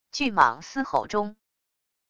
巨蟒嘶吼中wav音频